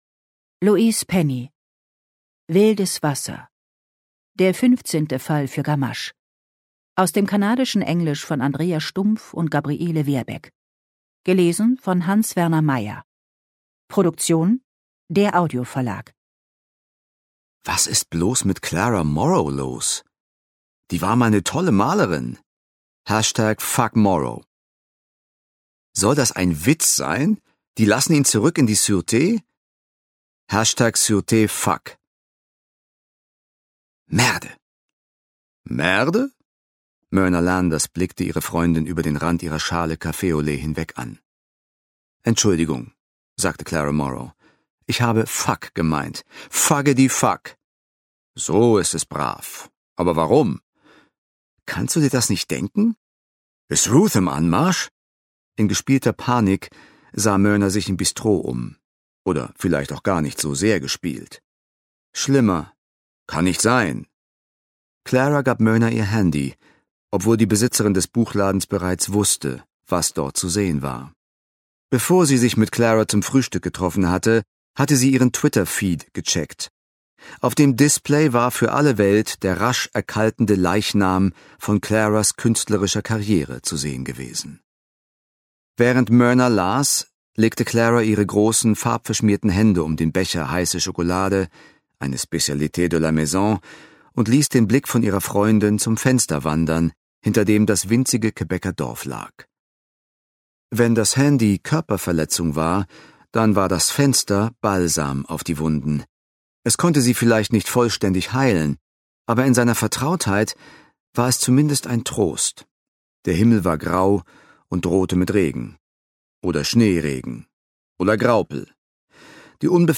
Ungekürzte Lesung mit Hans-Werner Meyer (2 mp3-CDs)
Hans-Werner Meyer (Sprecher)